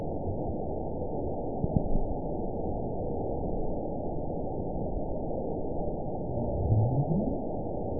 event 921918 date 12/22/24 time 19:49:21 GMT (5 months, 4 weeks ago) score 8.19 location TSS-AB04 detected by nrw target species NRW annotations +NRW Spectrogram: Frequency (kHz) vs. Time (s) audio not available .wav